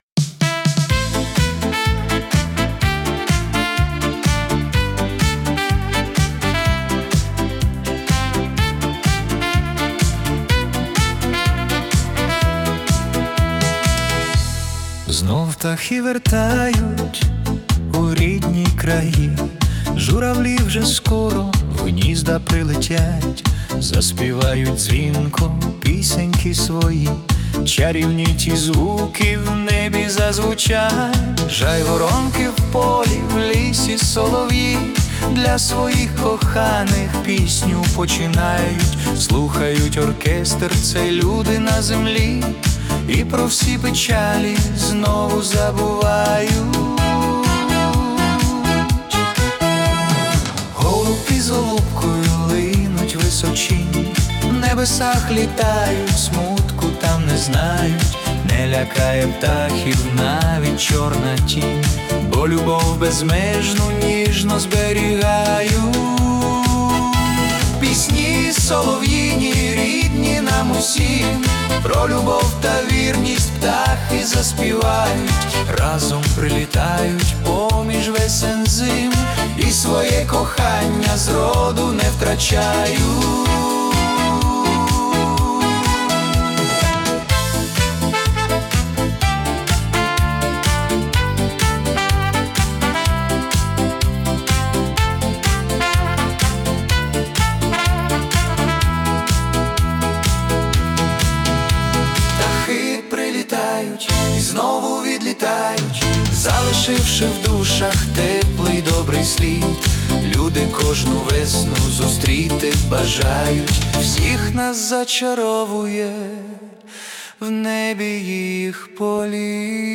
Світла естрадна композиція у жанрі ВІА 80-х.